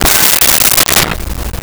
Glass Bottle Break 06
Glass Bottle Break 06.wav